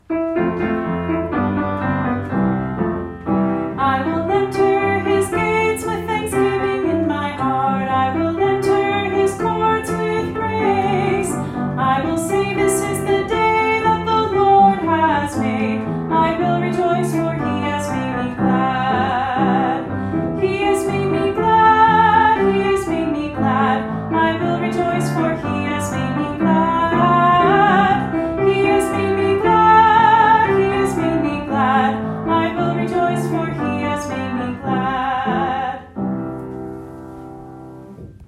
Scripture Songs